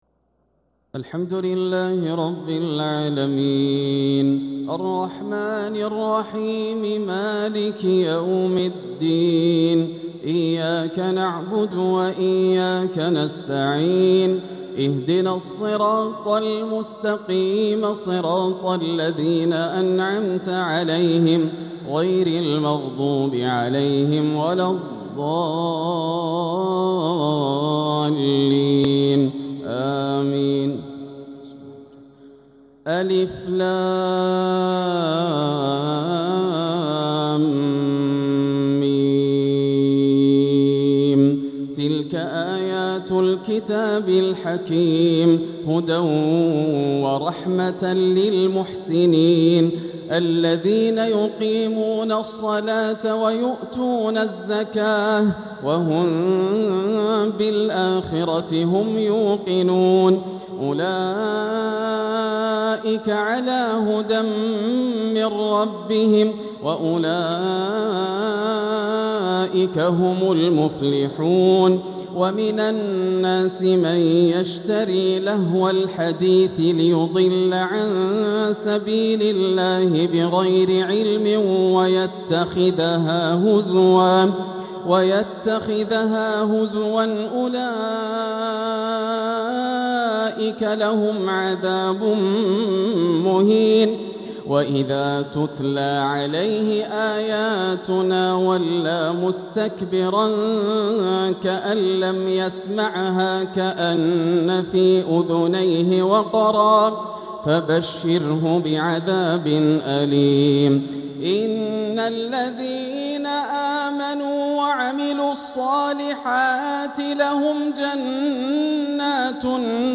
تهجد ليلة 29 رمضان 1446هـ سورة لقمان كاملة | Tahajjud 29th night Ramadan 1446H Surah Luqman > تراويح الحرم المكي عام 1446 🕋 > التراويح - تلاوات الحرمين